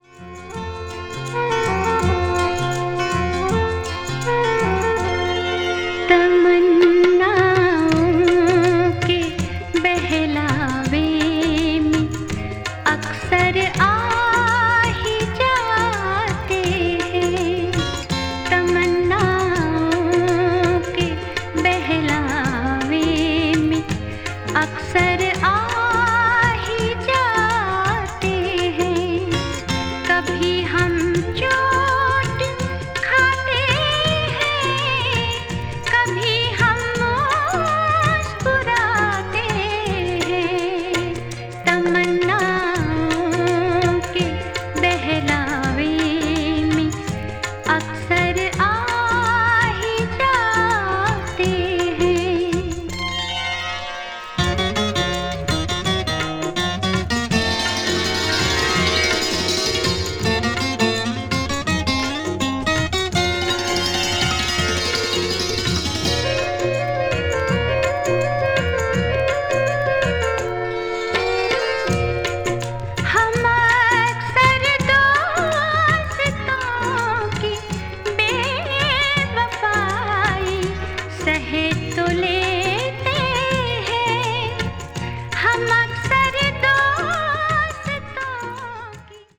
がざる和音や旋律は日本の歌謡曲にも近似するところがあるので、とても耳馴染みが良いです。
ghazal   india   indian pop   mellow groove   world music